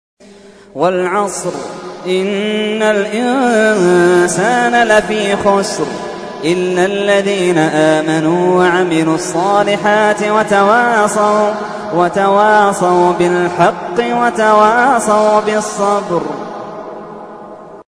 تحميل : 103. سورة العصر / القارئ محمد اللحيدان / القرآن الكريم / موقع يا حسين